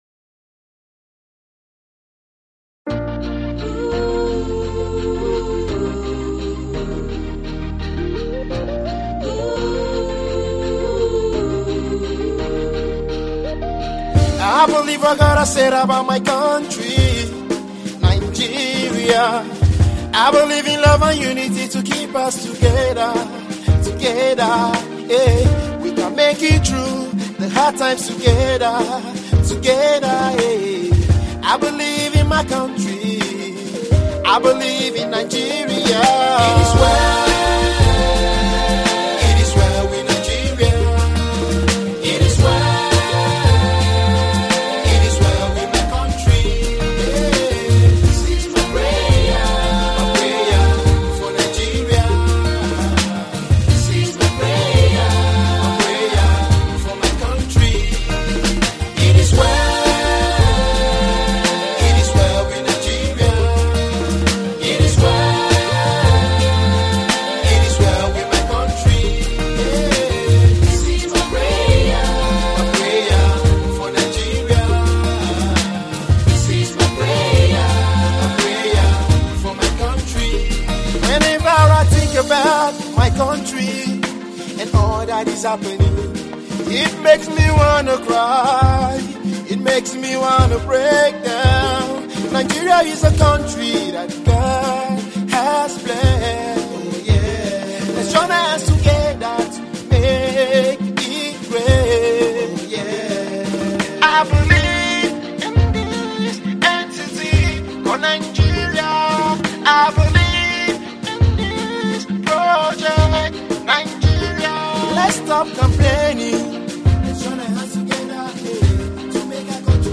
contemporary gospel